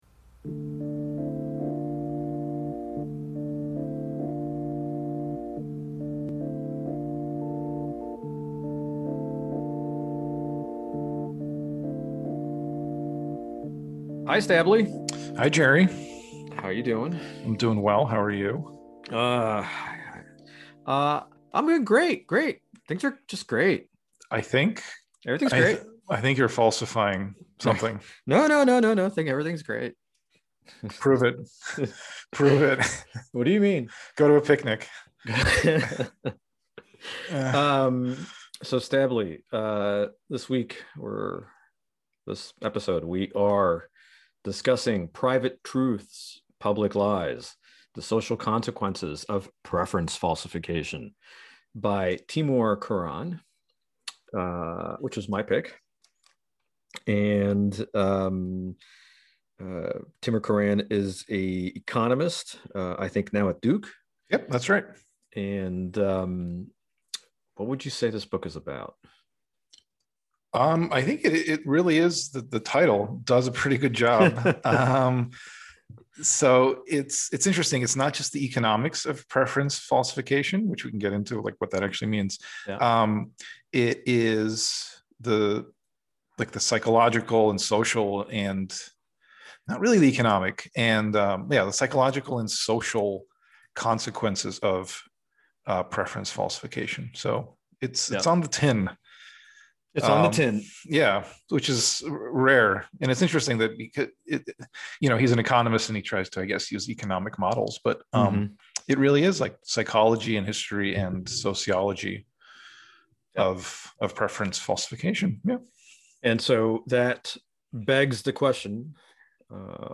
engage in a fortnightly conversation about a book they have recently read.